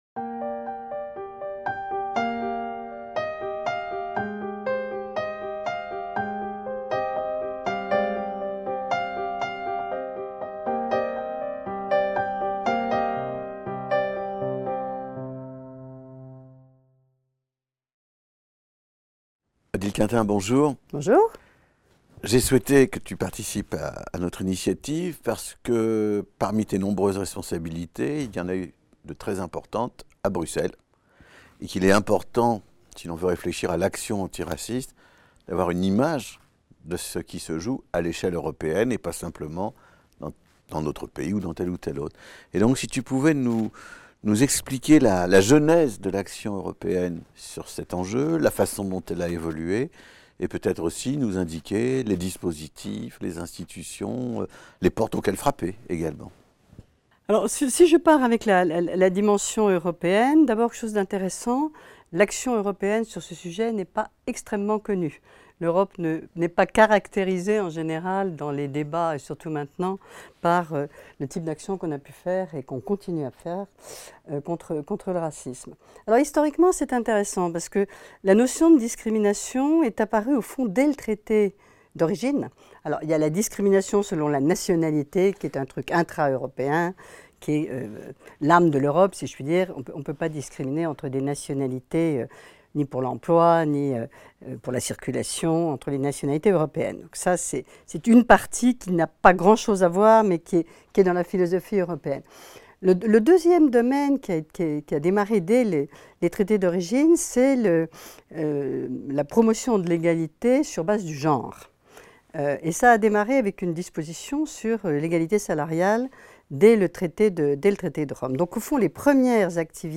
L'Union européenne en action - Un entretien avec Odile Quintin | Canal U